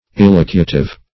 Elocutive \El"o*cu`tive\, a.